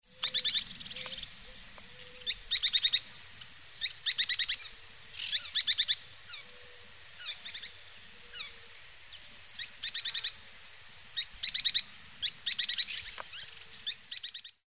Spix´s Spinetail (Synallaxis spixi)
Life Stage: Adult
Location or protected area: Delta del Paraná
Condition: Wild
Certainty: Observed, Recorded vocal